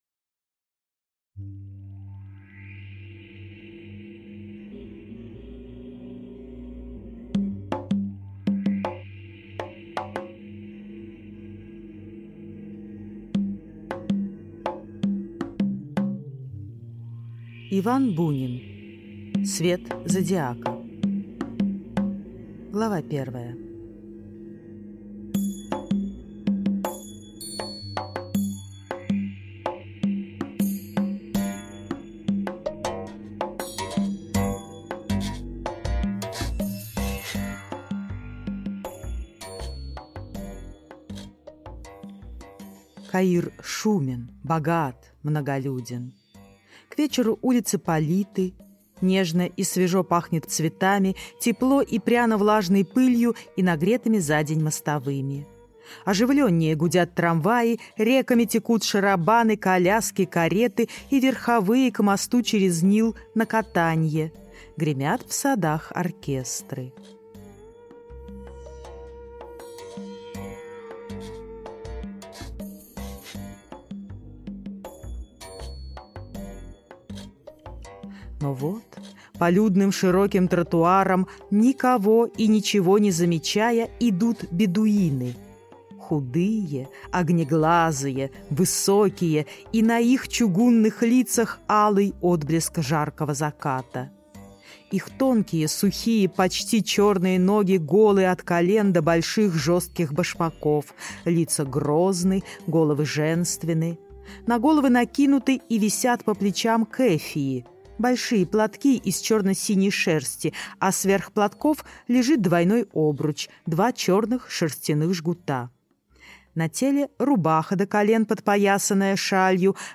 Аудиокнига Свет зодиака | Библиотека аудиокниг